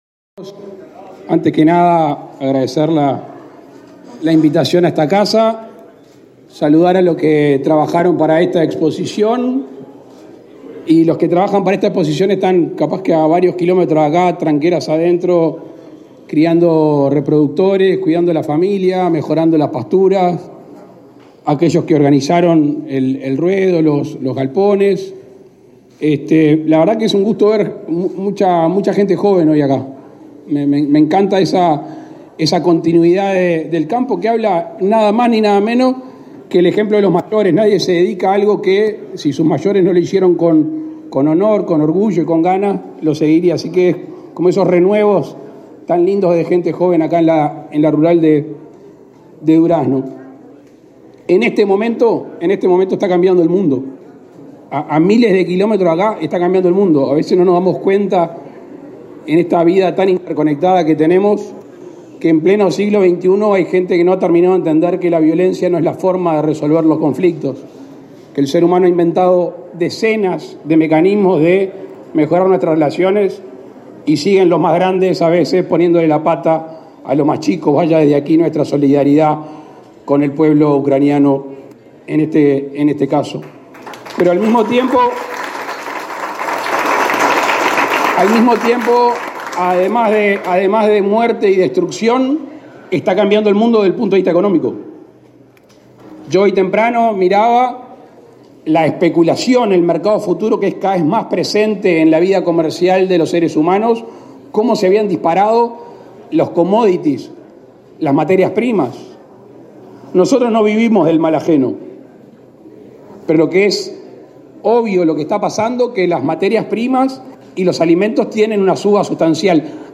Palabras del presidente de la República, Luis Lacalle Pou, en Durazno
El presidente de la República, Luis Lacalle Pou, participó, este jueves 24, en la inauguración de la 108.ª Expo Durazno, que se realiza hasta el